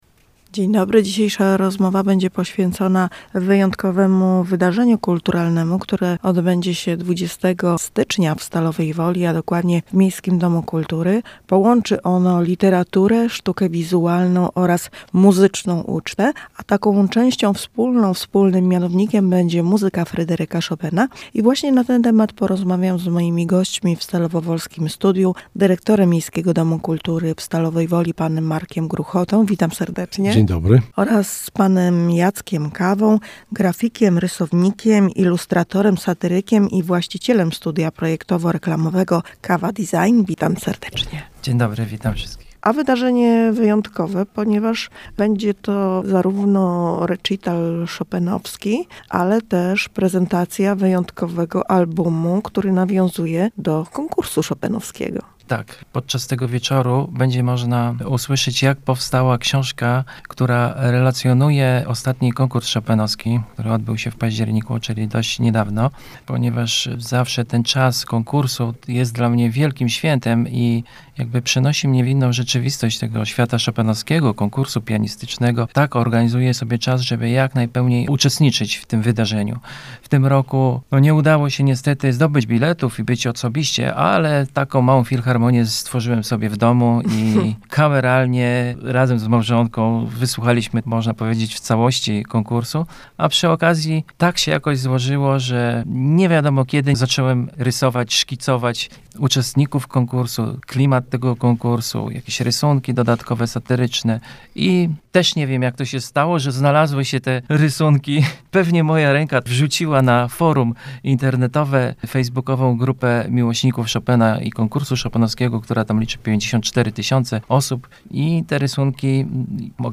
Rozmowa o wydarzeniu nawiązującym do Konkursu Chopinowskiego, które już 20 stycznia w MDK w Stalowej Woli.